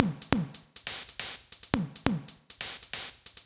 Ridim Hats 138bpm.wav